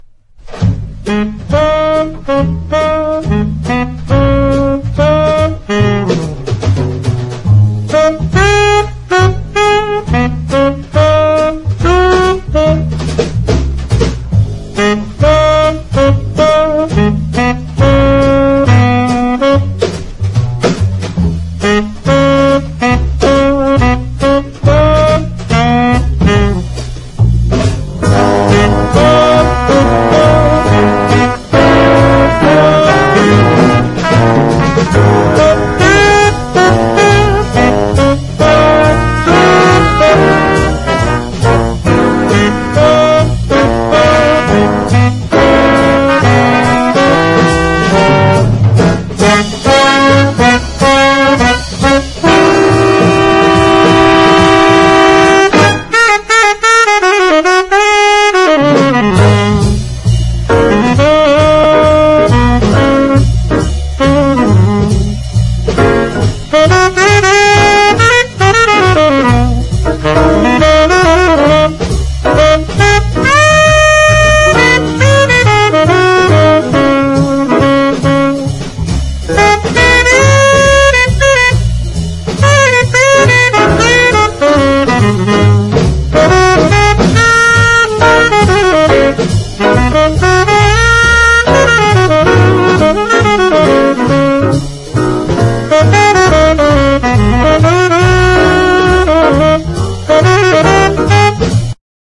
美しいギター・カッティングで幕開けるブラジリアン・ダンサー